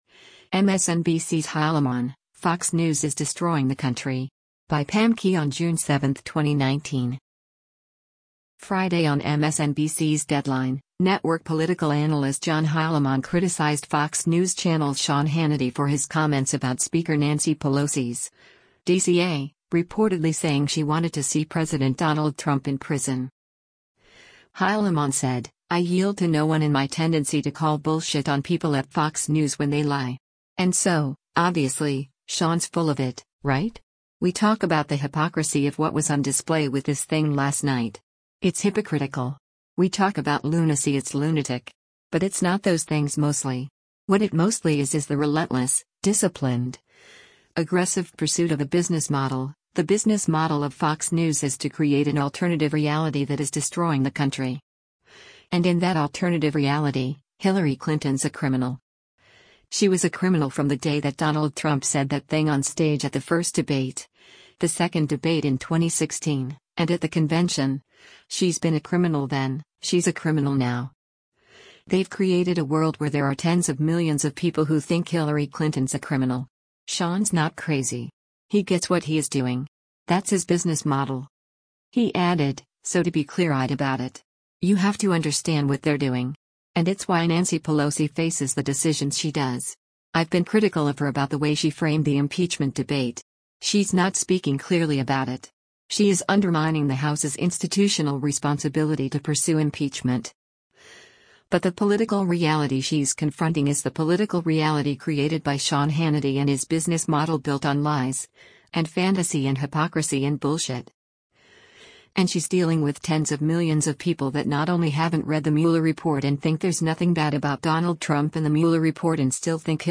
Friday on MSNBC’s “Deadline,” network political analyst John Heilemann criticized Fox News Channel’s Sean Hannity for his comments about Speaker Nancy Pelosi’s (D-CA) reportedly saying she wanted to see President Donald Trump in prison.